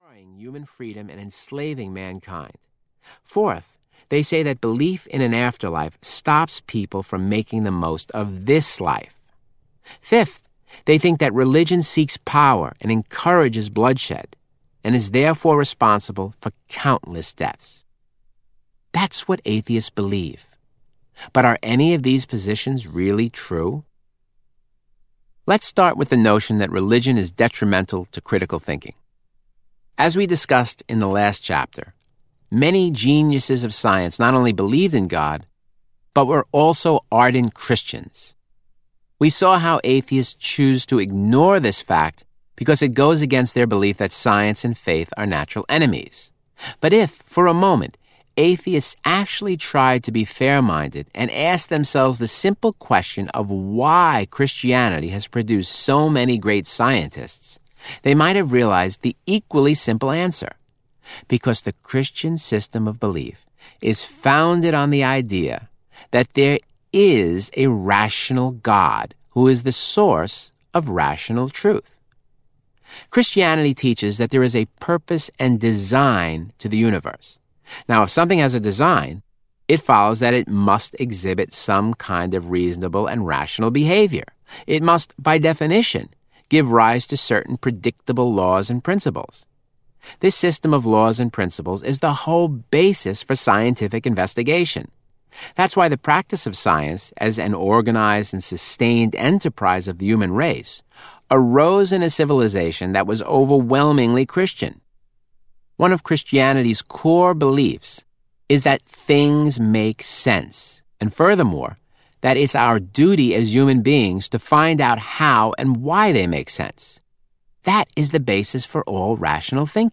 Inside the Atheist Mind Audiobook
Narrator
7.63 Hrs. – Unabridged